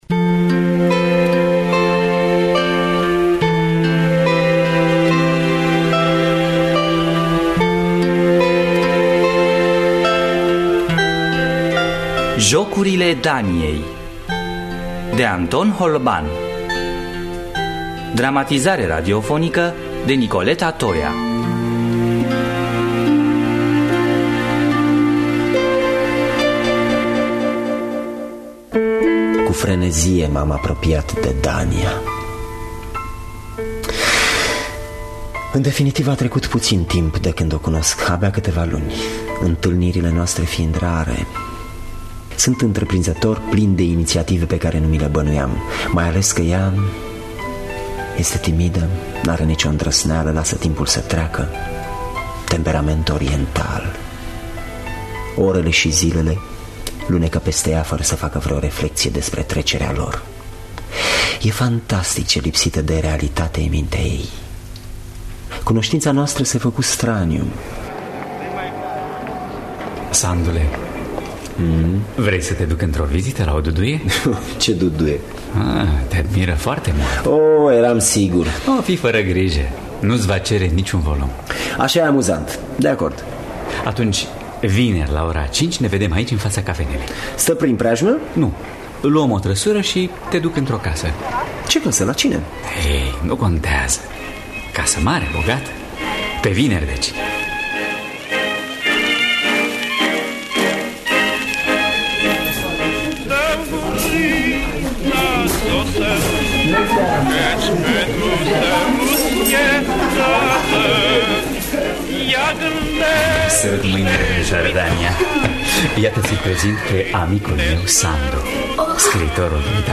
Jocurile Daniei de Anton Holban – Teatru Radiofonic Online